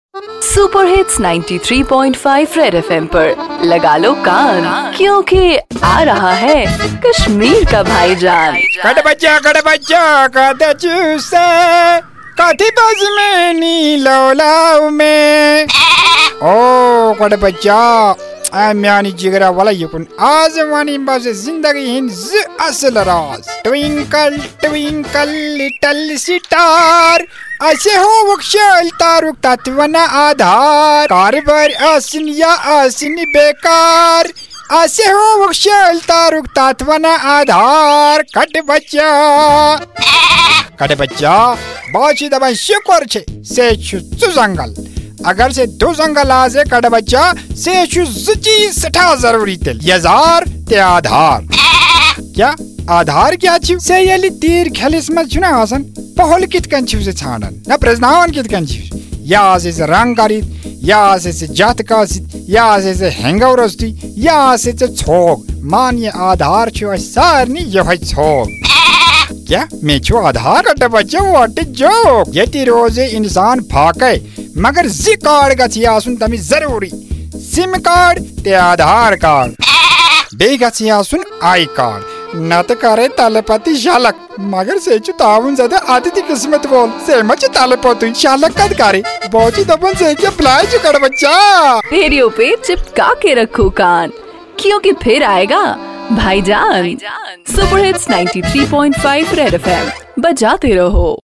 Bhaijaan the ultimate dose of comedy in Kashmir which is high on satire and humor .